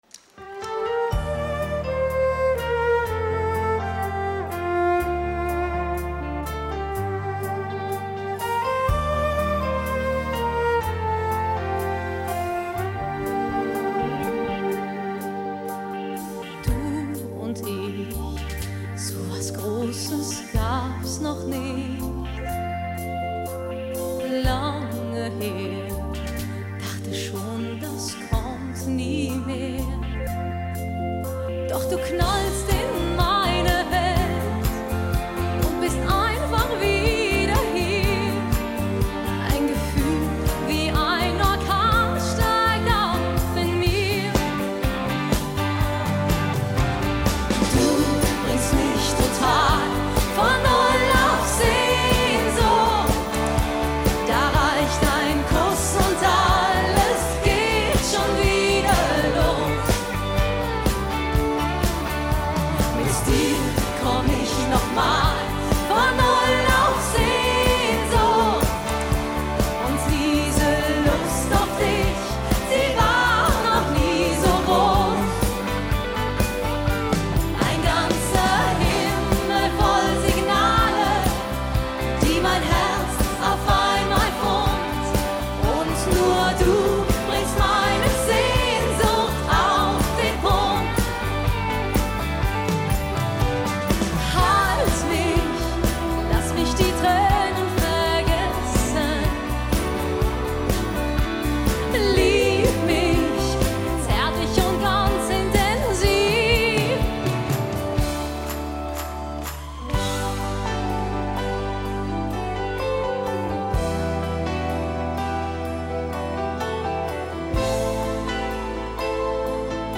Genre: Pop, Schlager